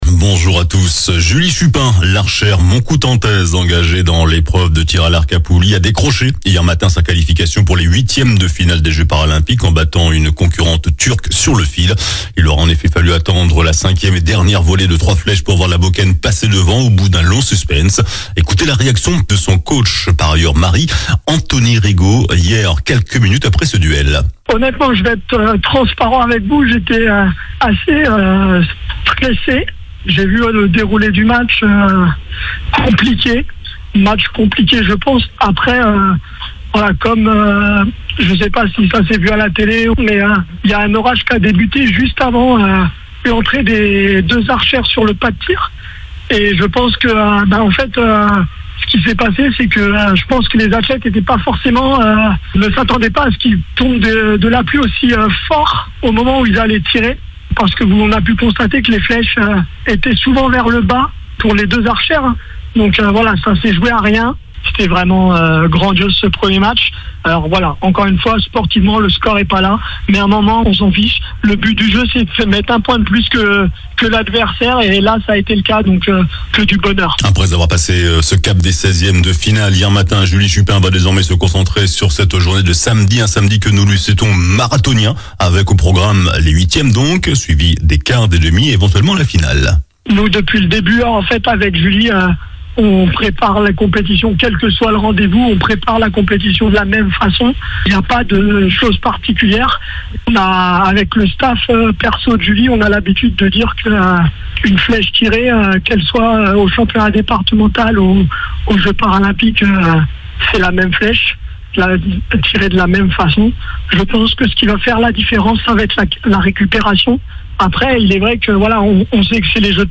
JOURNAL DU SAMEDI 31 AOÛT